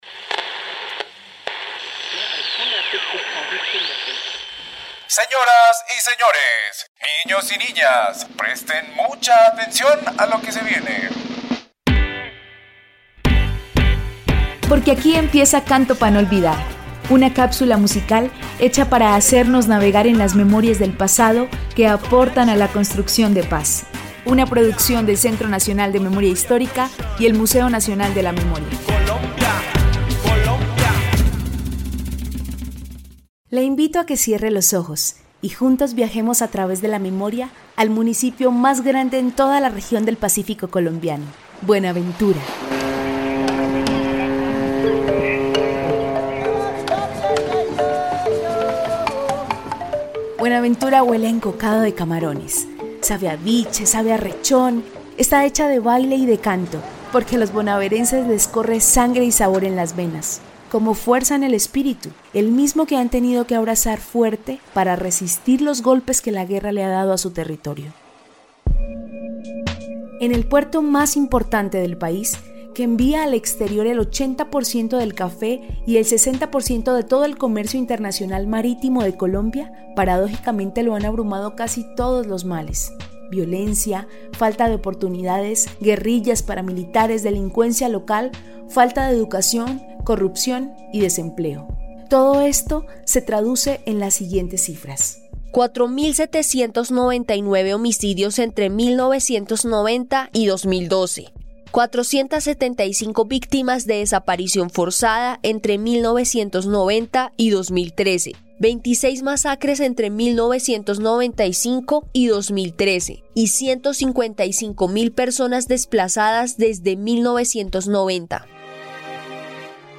Canción inspirada en la violencia del pacifico colombiano.